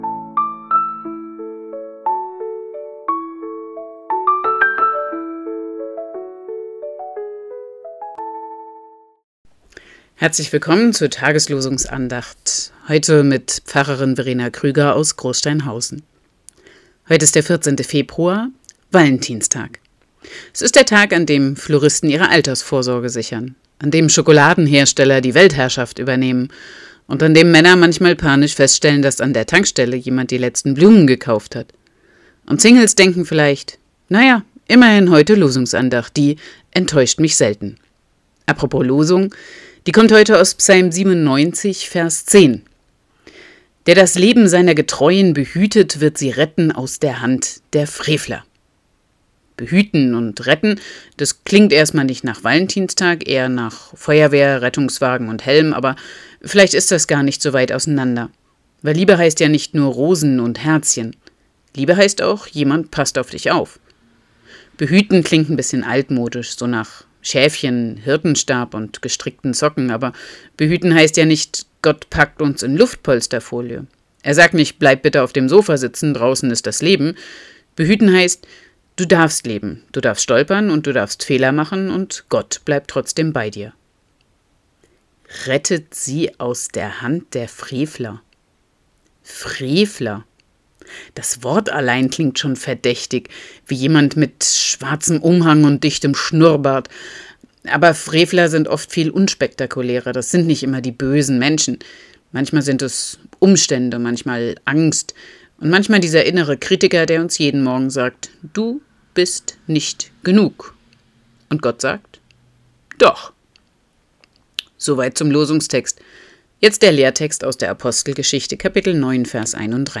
Losungsandacht für Samstag, 14.02.2026